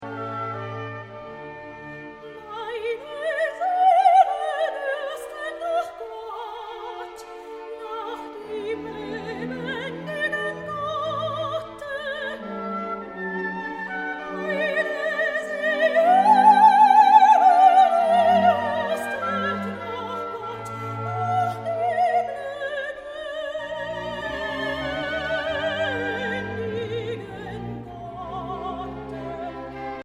Sopran
Orgel
Oboe und Englischhorn
Violoncello